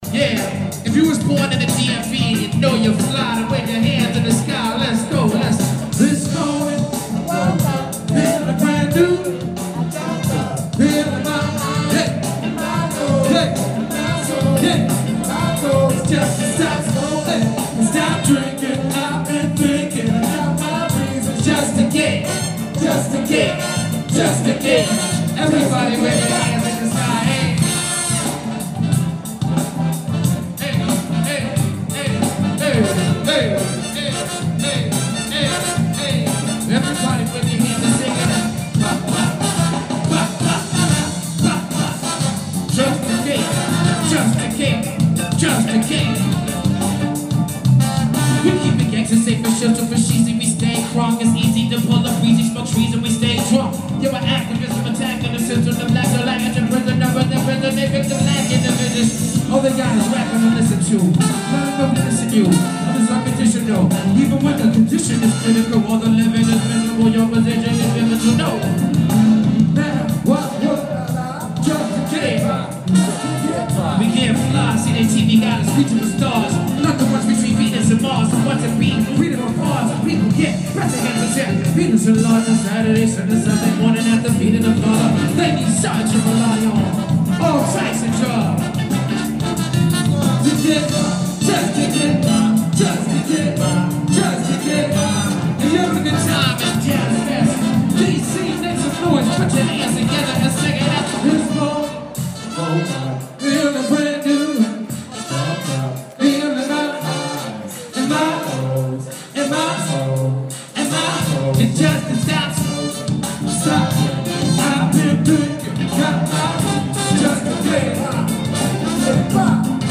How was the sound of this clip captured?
19th June 2016, DC Jazz Fest